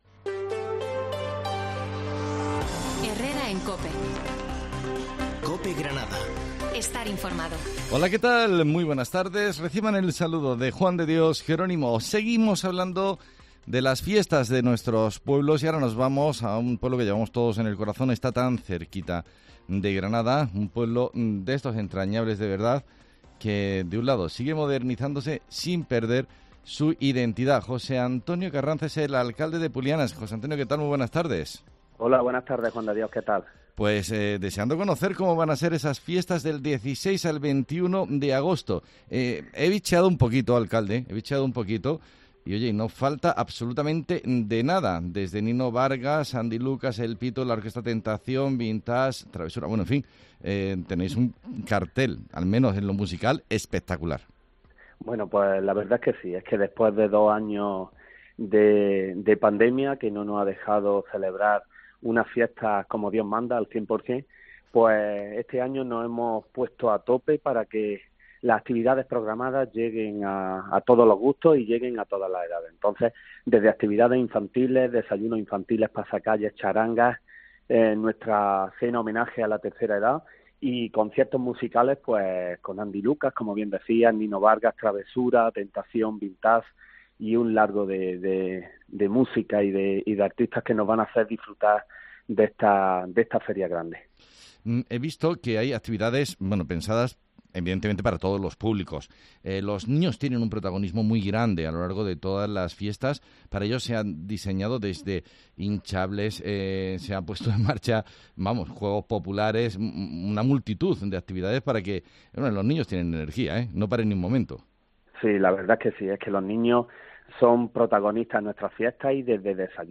El alcalde de Pulianas nos cuenta cómo van a ser las fiestas patronales que arrancan el martes 16